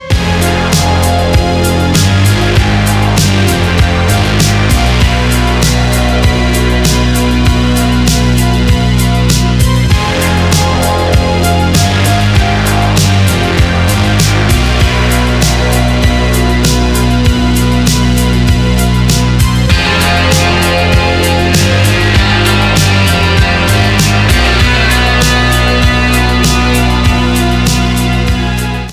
• Качество: 320, Stereo
Electronic
пугающие
тревожные
Bass
устрашающие